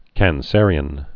(kăn-sĕrē-ən)